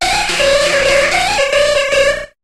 Cri de Lippoutou dans Pokémon HOME.